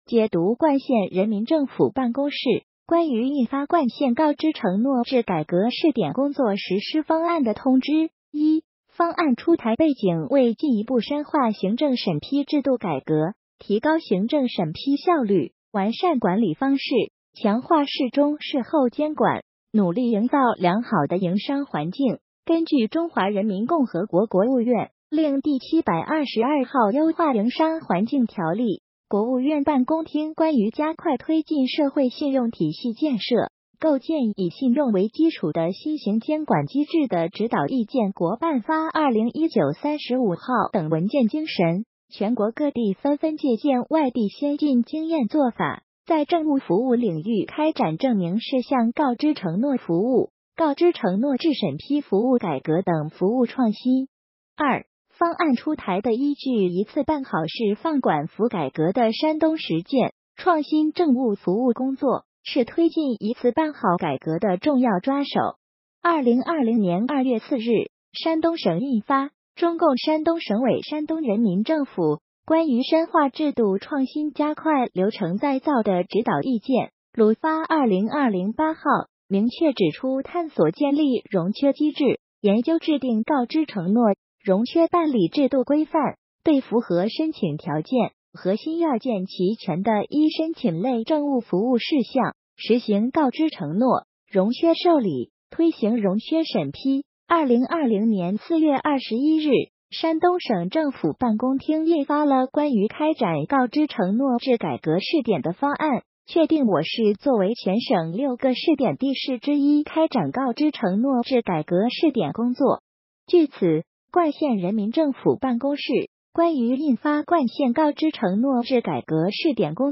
音频解读：冠县人民政府办公室关于印发《冠县告知承诺制改革试点工作实施方案》的通知.mp3